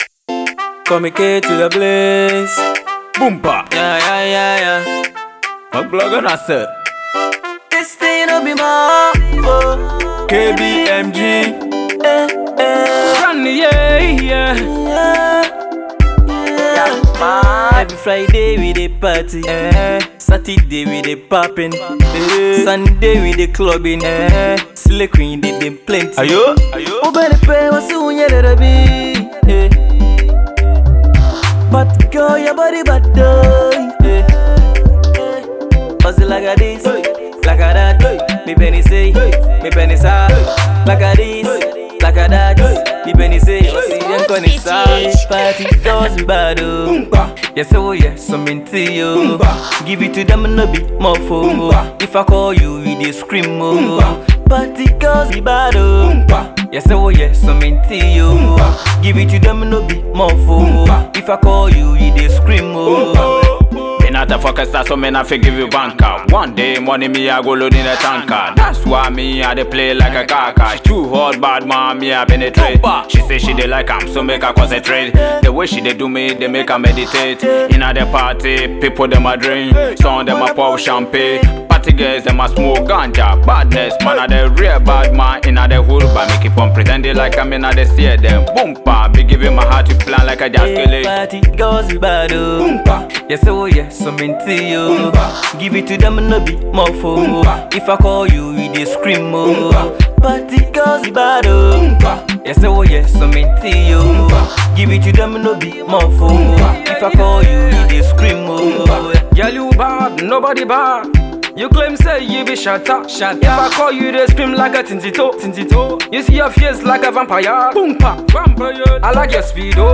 party tune